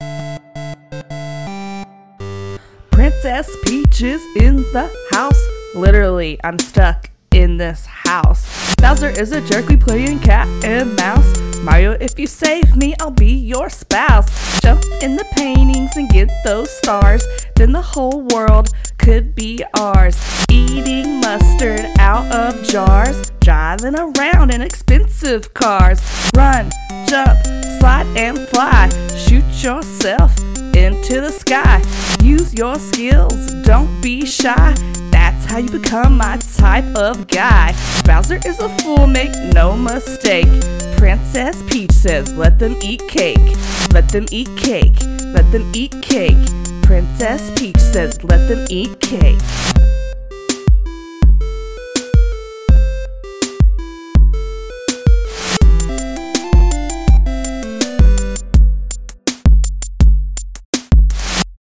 Rap from Episode 34: Super Mario 64 – Press any Button
Mario-64-Rap.mp3